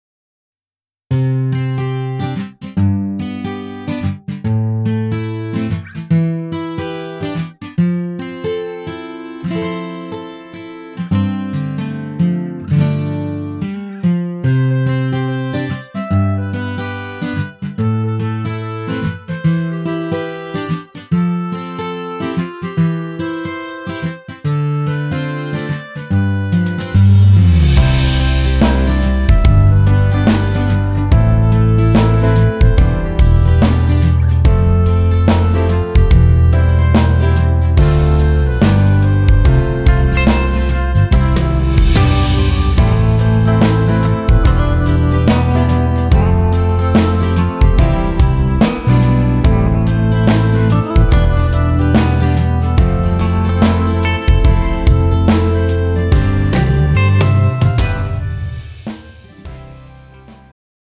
음정 원키
장르 축가 구분 Pro MR